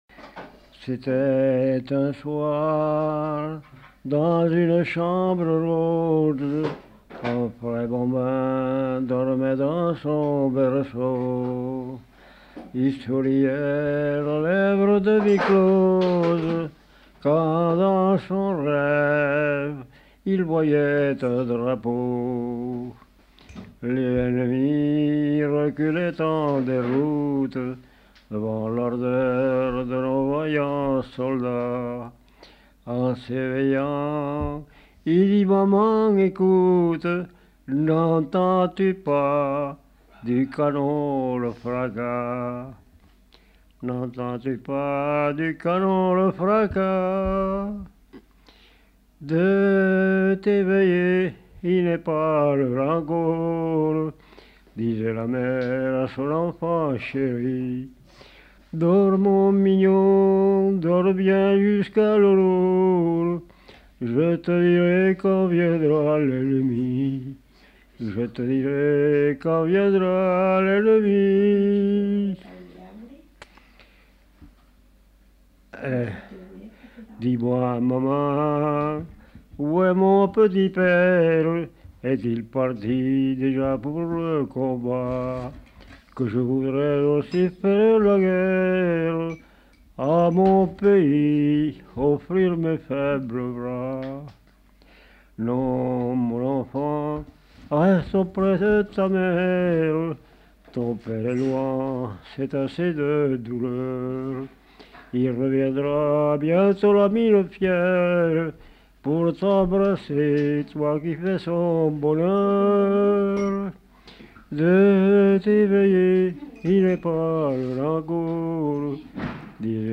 Lieu : Faudoas
Genre : chant
Type de voix : voix d'homme
Production du son : chanté
Classification : chanson patriotique